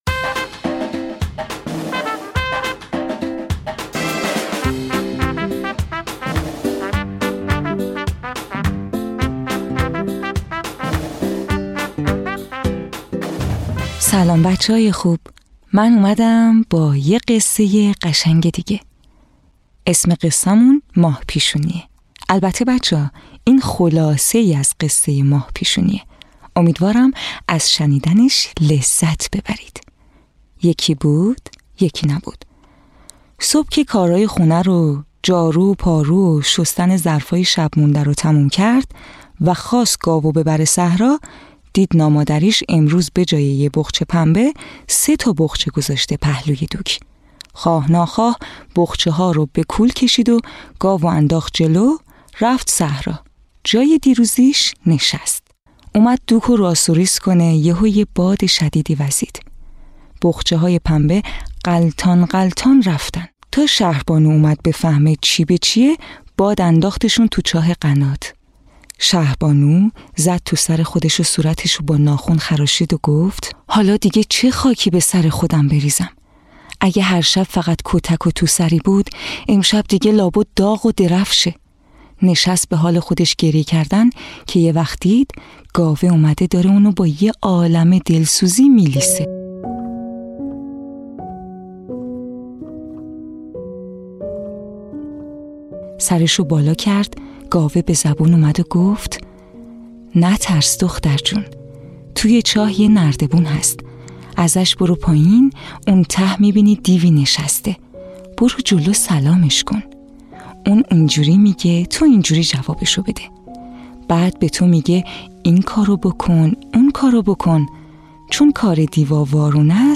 قصه های کودکانه صوتی- این داستان: ماه پیشونی
تهیه شده در استودیو نت به نت